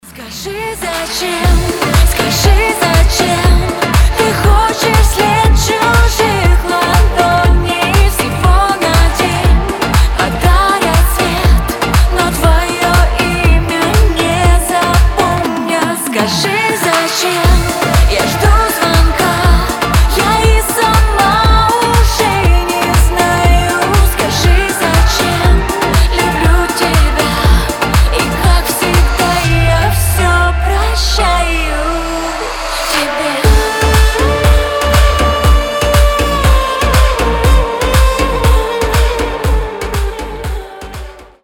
поп
женский вокал
восточные мотивы